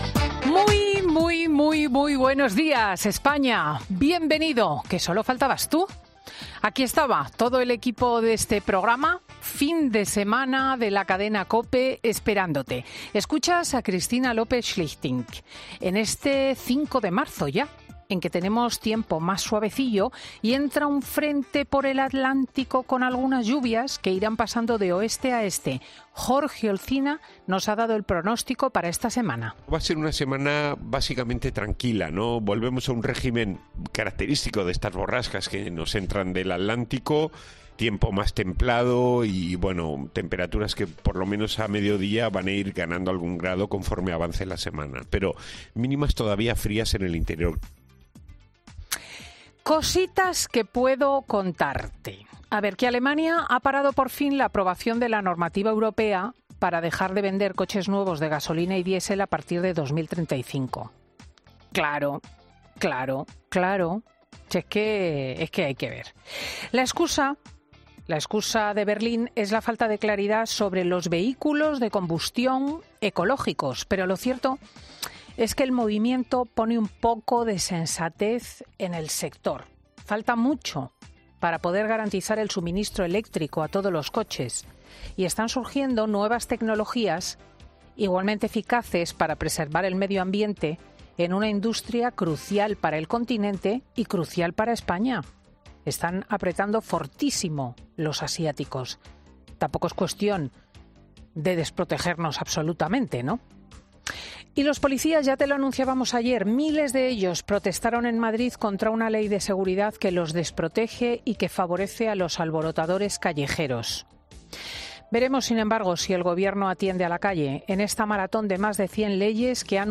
La comunicadora desgrana los temas que marcan la actualidad de este domingo, 5 de marzo